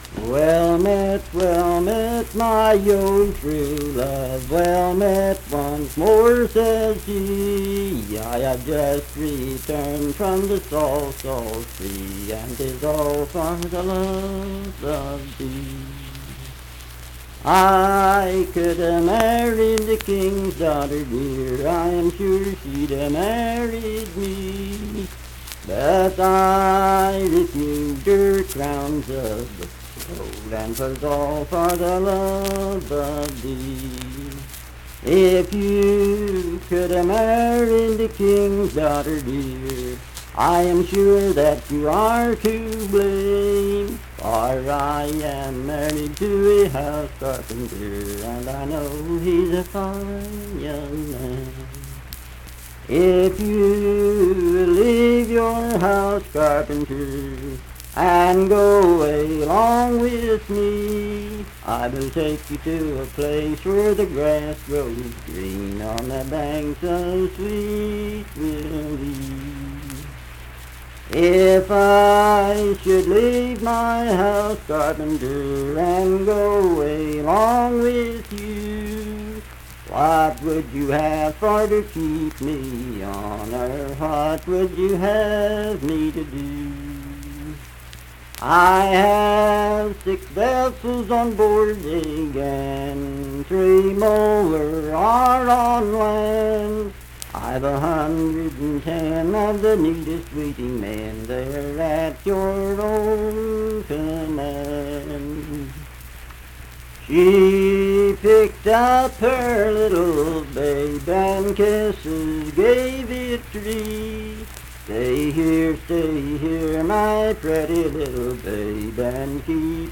Unaccompanied vocal music
Verse-refrain 13(4).
Voice (sung)